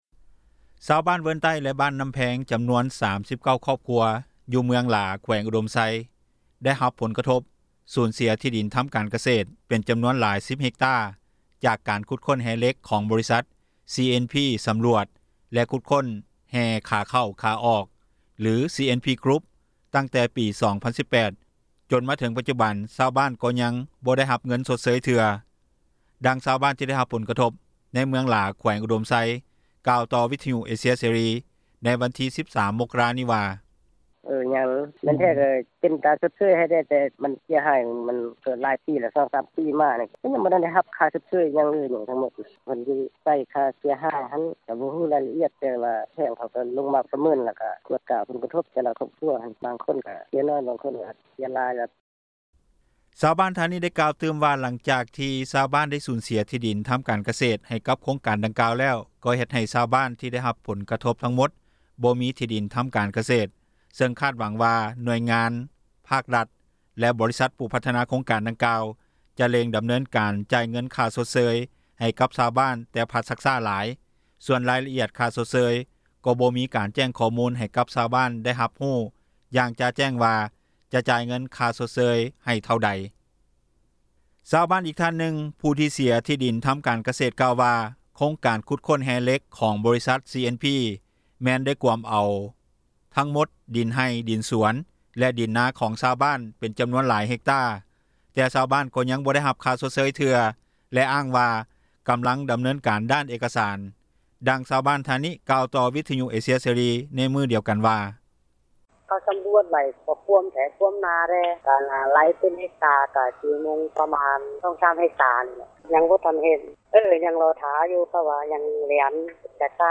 ດັ່ງຊາວບ້ານທີ່ໄດ້ຮັບຜົນກະທົບ ໃນເມືອງຫລາ ແຂວງອຸດົມໄຊ ກ່າວຕໍ່ວິທຍຸ ເອເຊັຽເສຣີ ໃນວັນທີ 13 ມົກຣານີ້ວ່າ:
ດັ່ງຊາວບ້ານທ່ານນີ້ ກ່າວຕໍ່ວິທຍຸ ເອເຊັຽ ເສຣີ ໃນມື້ດຽວກັນວ່າ:
ດັ່ງເຈົ້າໜ້າທີ່ ກ່າວຕໍ່ວິທຍຸ ເອເຊັຽເສຣີວ່າ: